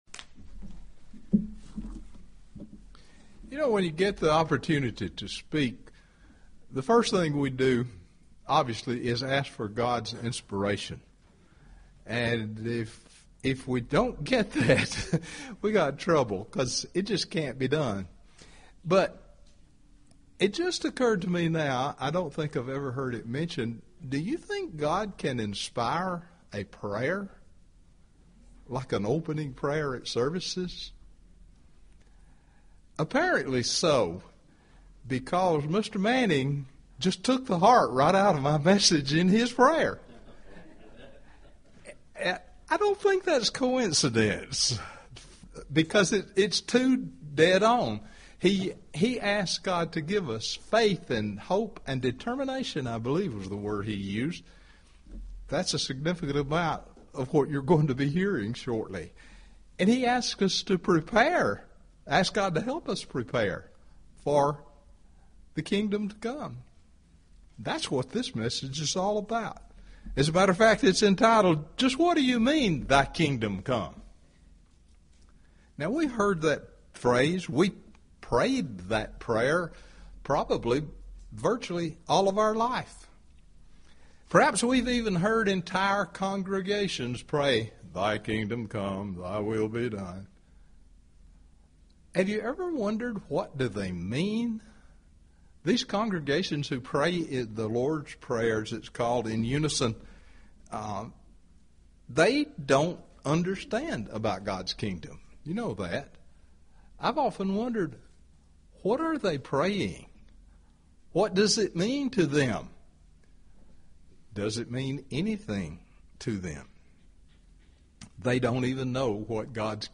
We need to ask God to help us grasp the significance of His coming kingdom. This sermon addresses the importance of asking this question as well as our role in God’s everlasting kingdom.